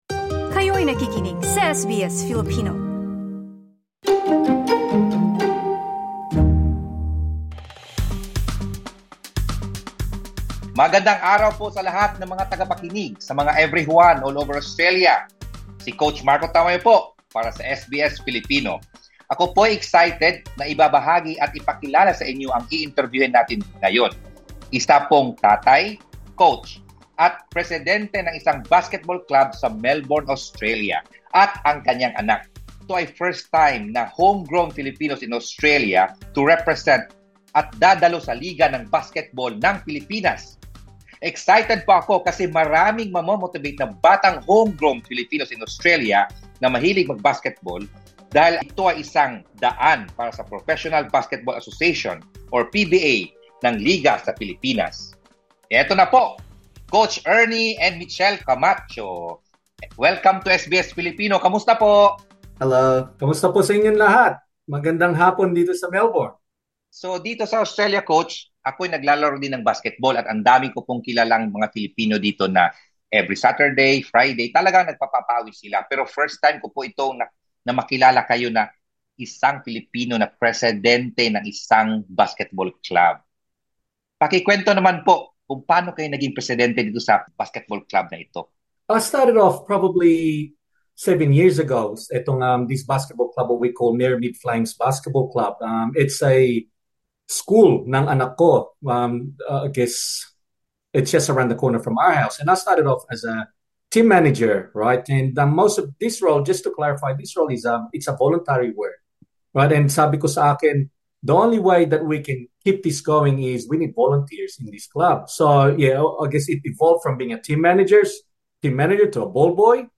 In an interview with SBS Filipino